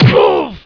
HIT01.WAV